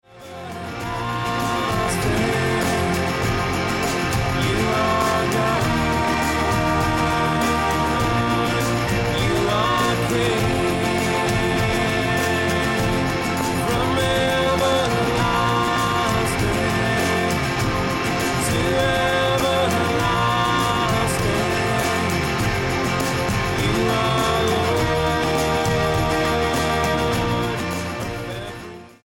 STYLE: Pop
is all electric guitars and interesting drums and loops